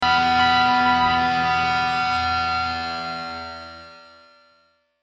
tone.mp3